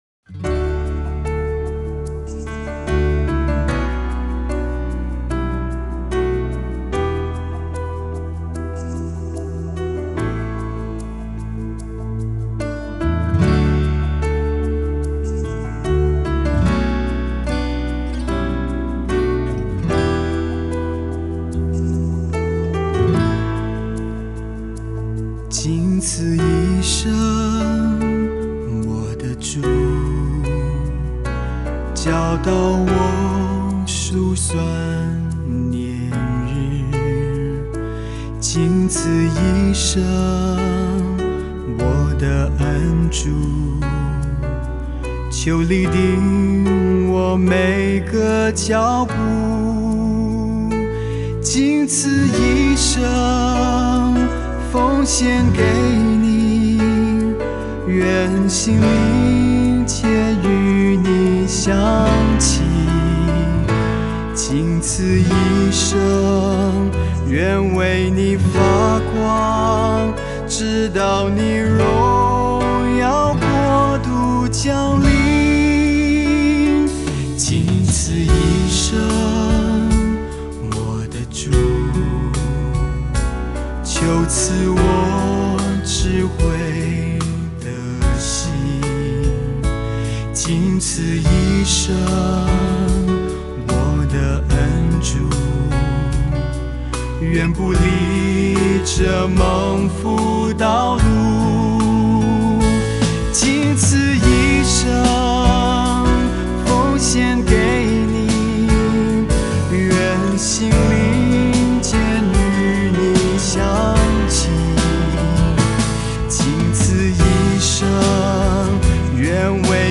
前奏 → 主歌 1 → 副歌 → 主歌 2 → 副歌 → 間奏 → 副歌 → 主歌 2 → 副歌(兩遍) → 副歌後半